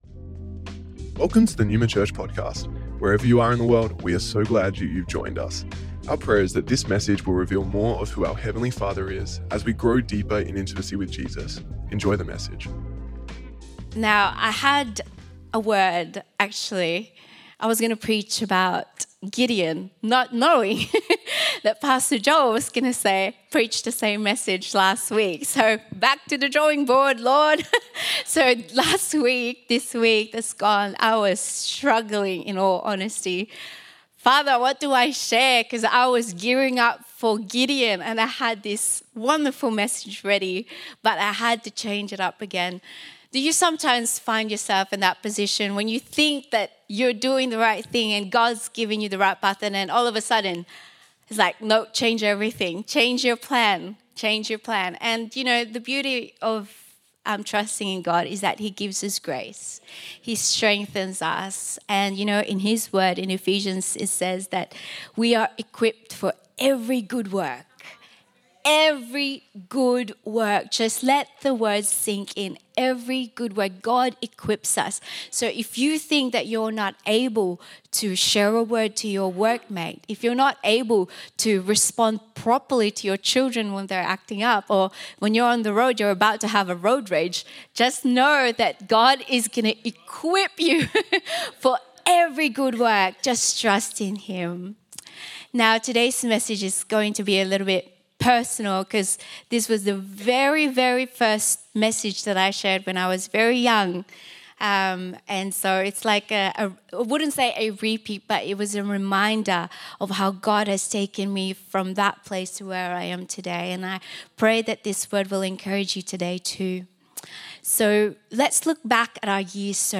Originally recorded at Neuma Melbourne West August 25th 2024